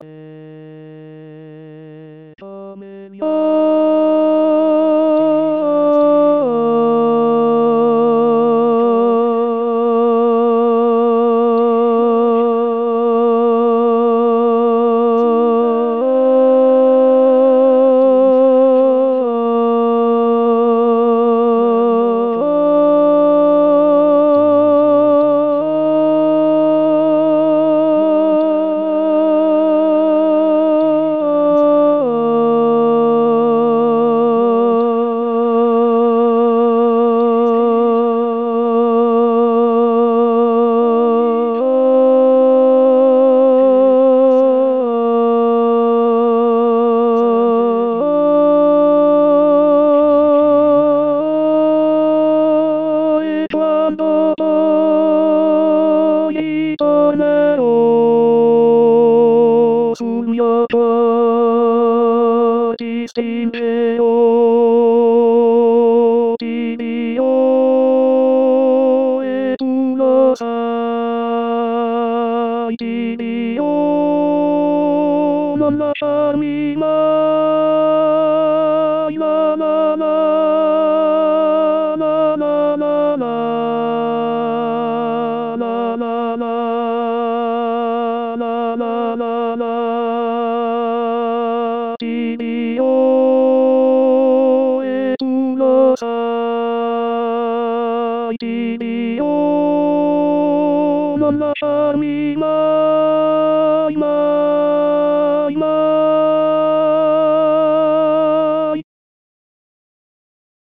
La Ballata del soldato tenors 1.mp3